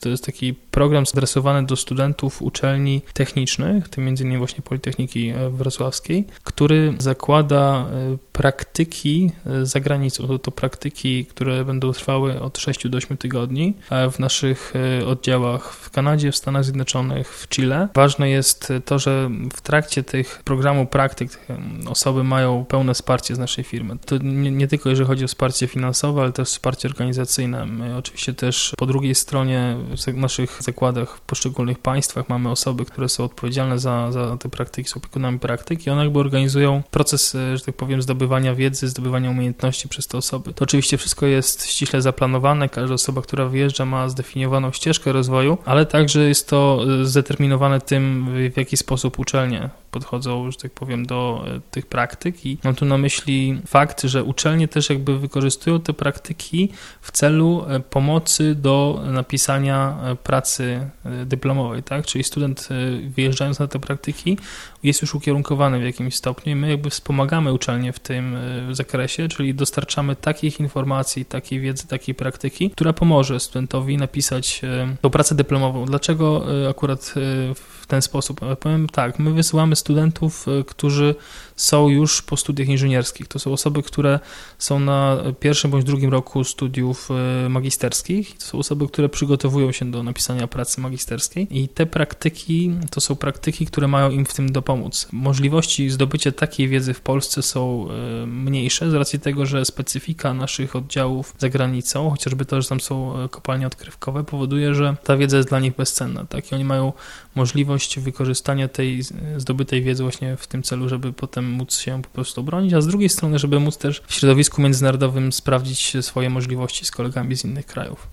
O programie praktyk w Akademickim Radiu Luz Politechniki Wrocławskiej opowiadał